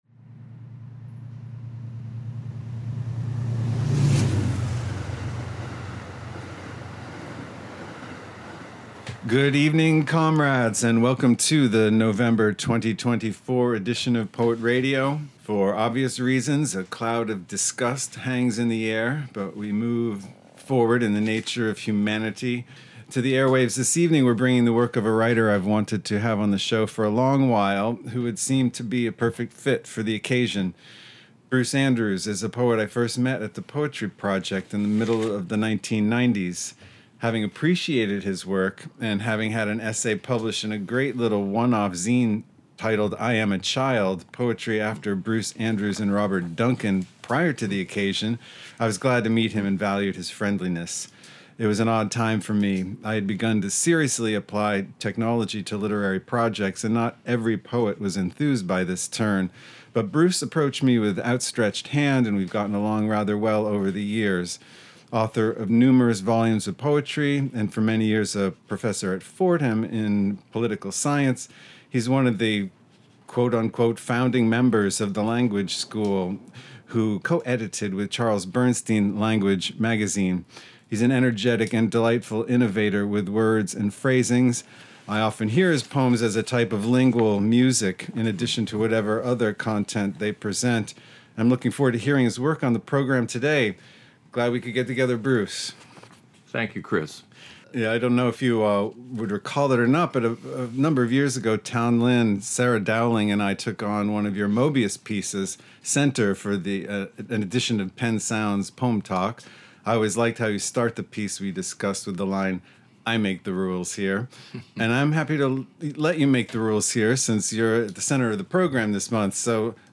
He is known for his association with Language poetry, and co-edited, with Charles Bernstein, L=A=N=G=U=A=G=E magazine. Blending words and sounds, bringing to the airwaves live performances as well as field and studio recordings by writers the host has crossed paths with over the course of a quarter century.